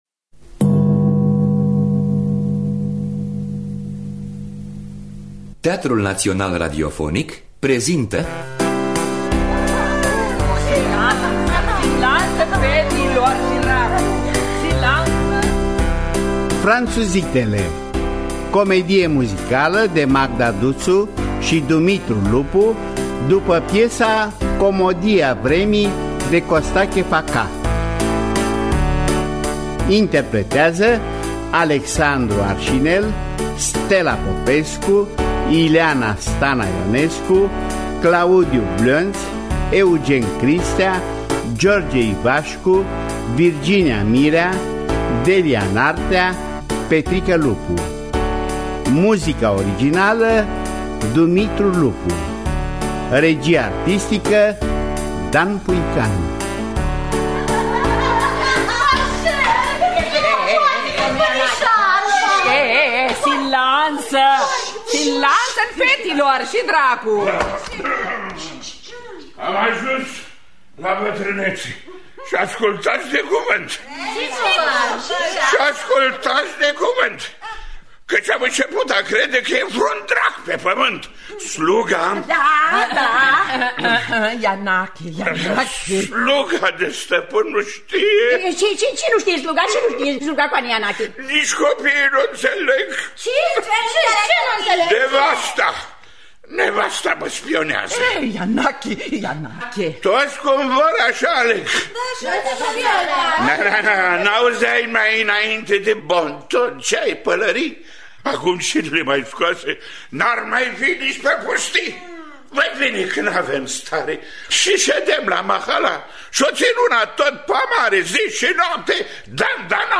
Scenariu muzical